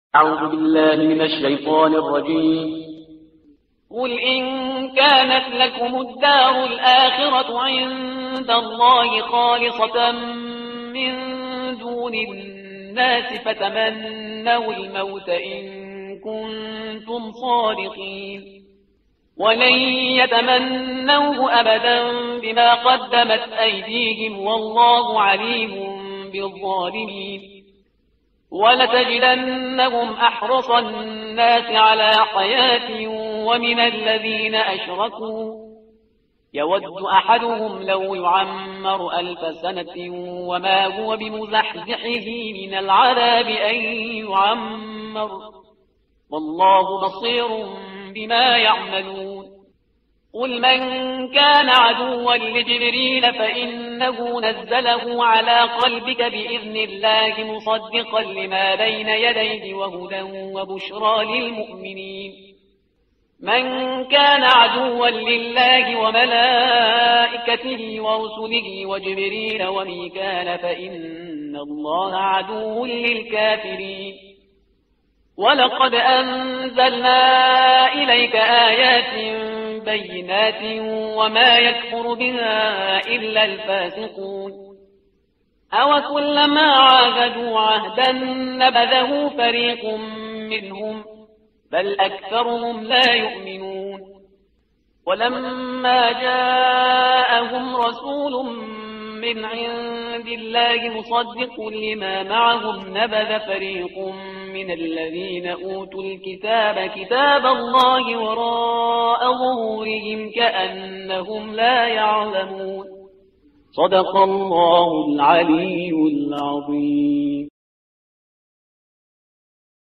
ترتیل صفحه 15 قرآن – جزء اول سوره بقره